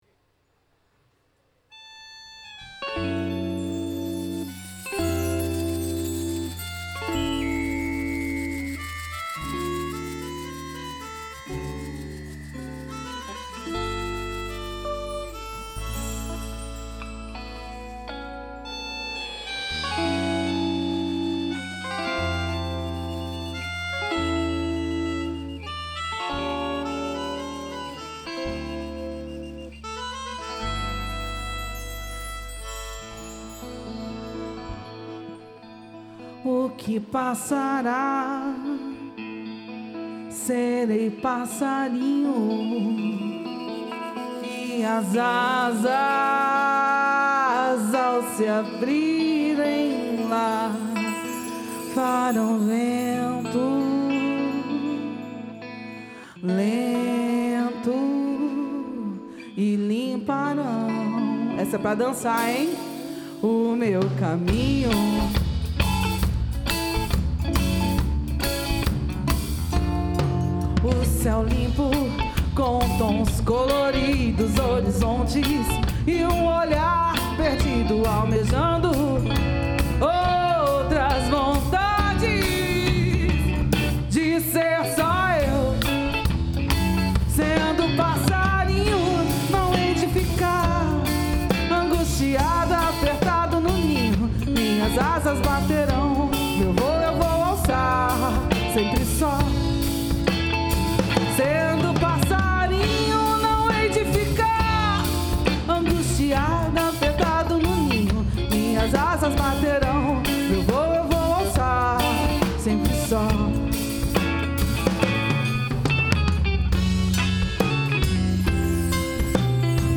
uma música